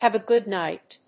アクセントhàve a góod [bád] níght
have+a+good+night.mp3